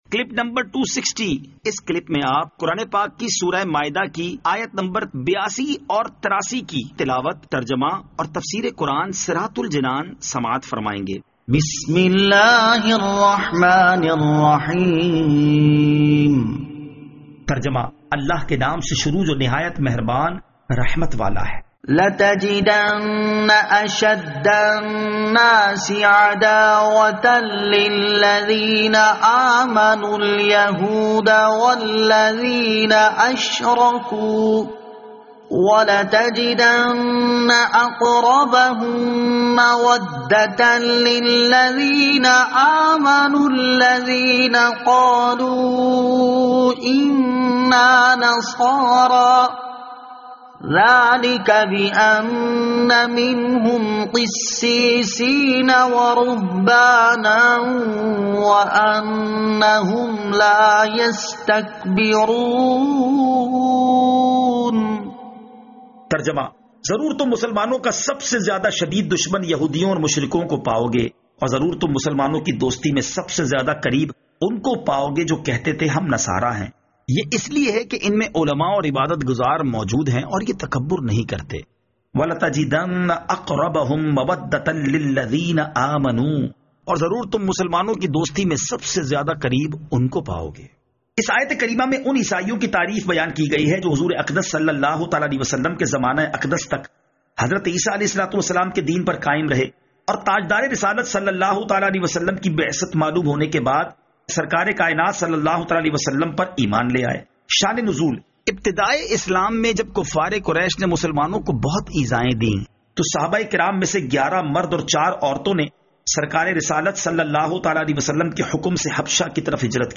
Surah Al-Maidah Ayat 82 To 83 Tilawat , Tarjama , Tafseer
2020 MP3 MP4 MP4 Share سُوَّرۃُ ٱلْمَائِدَة آیت 82 تا 83 تلاوت ، ترجمہ ، تفسیر ۔